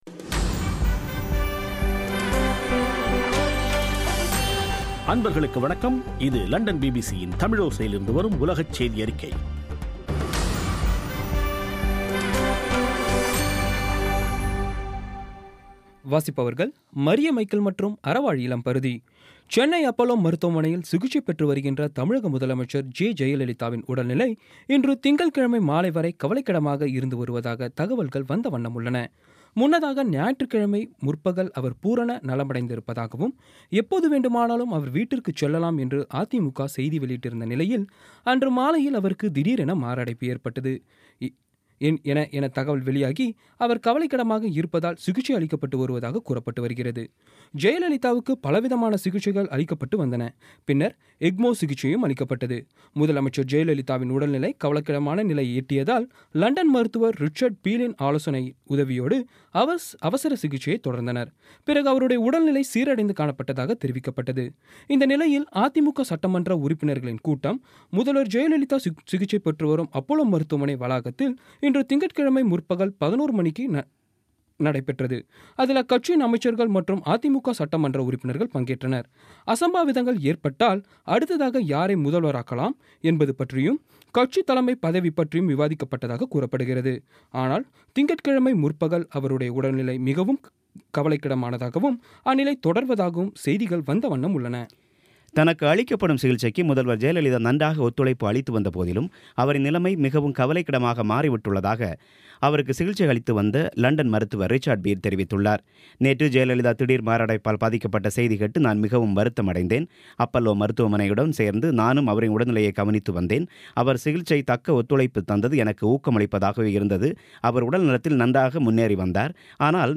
பி பி சி தமிழோசை செய்தியறிக்கை (05/12/16)